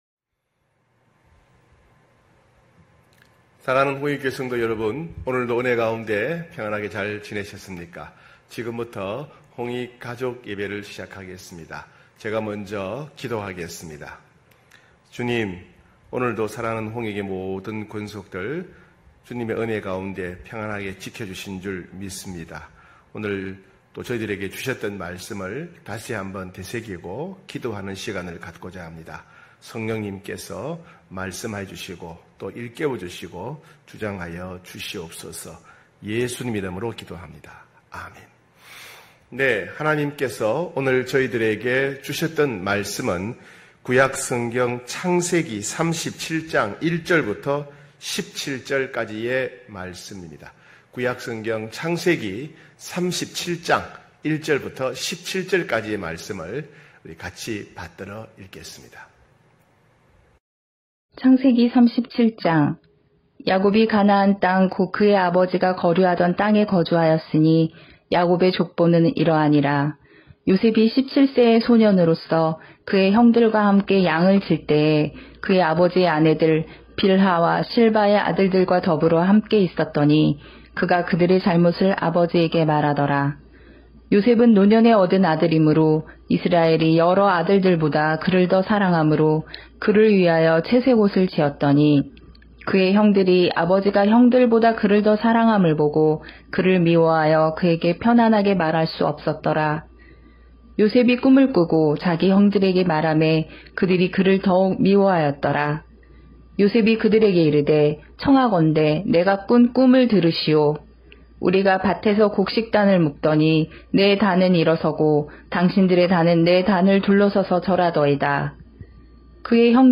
9시홍익가족예배(9월1일).mp3